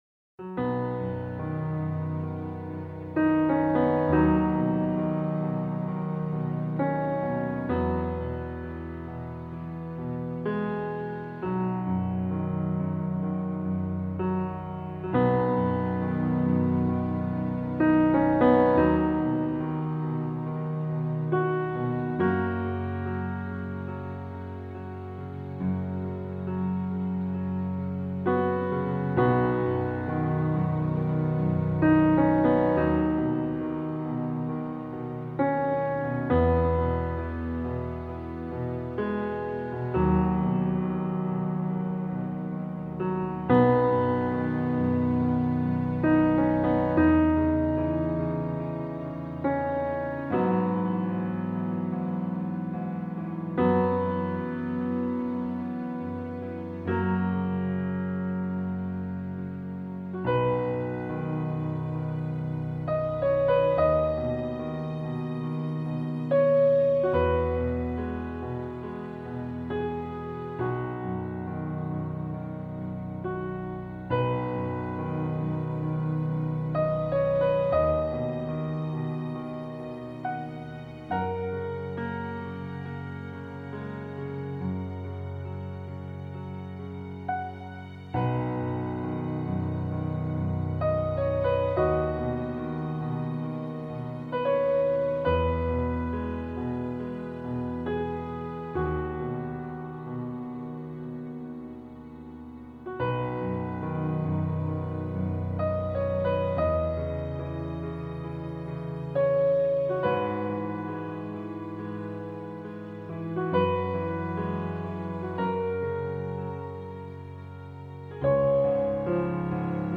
funeral-music-amazing-grace.mp3